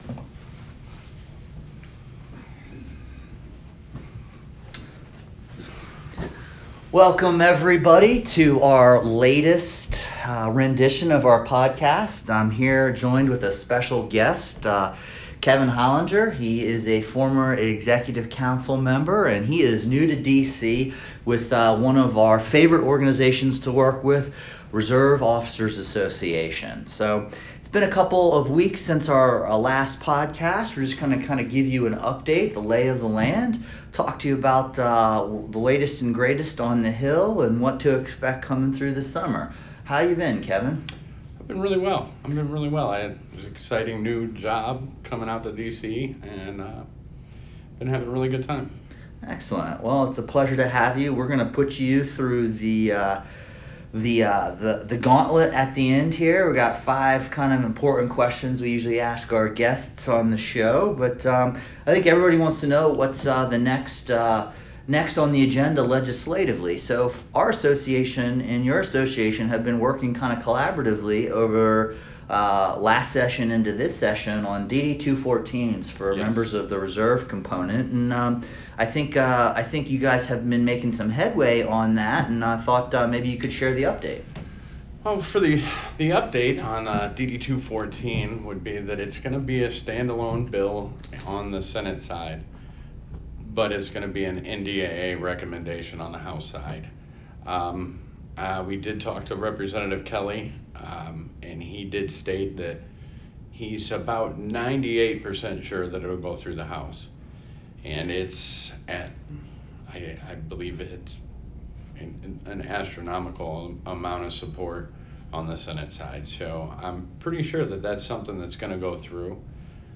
In our podcast we discuss relevant policy issues, answer your questions, and interview today’s leaders in government.